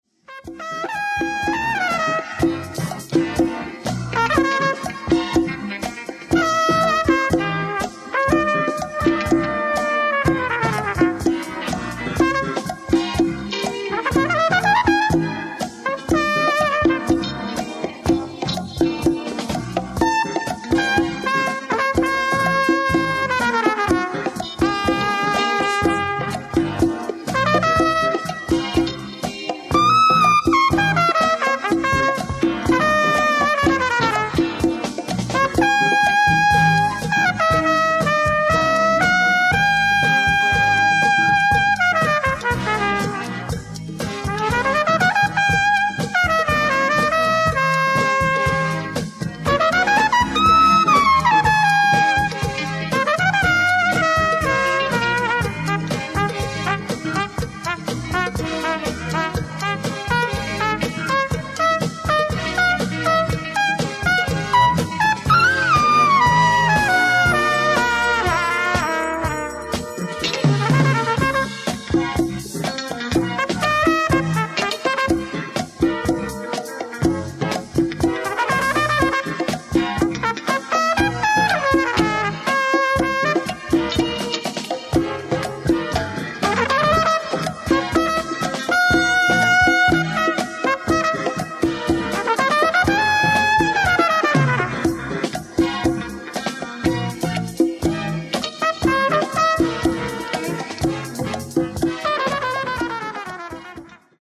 ライブ・アット・ジャス・サミット、ヴィーゼン、オーストリア 07/07/1984
※試聴用に実際より音質を落としています。
(Soundboard Version)